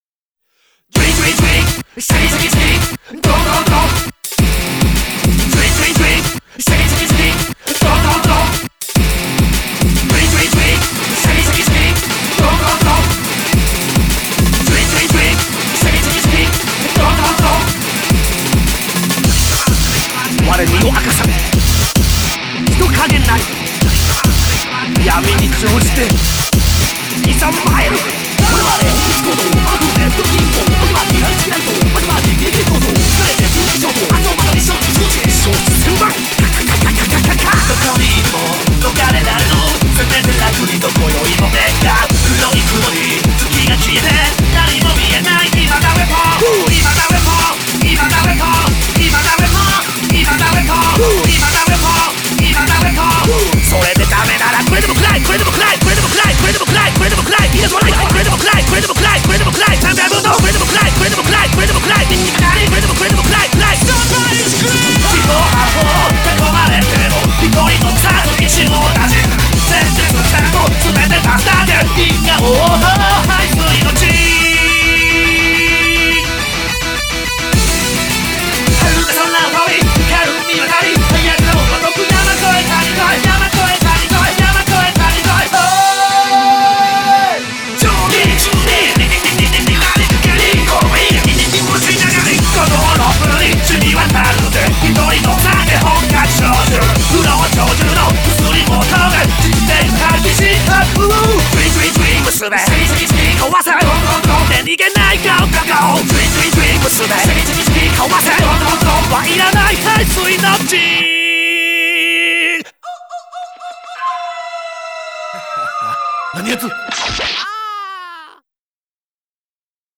BPM210
Audio QualityPerfect (High Quality)
Genre: NINJA METAL POPCONE.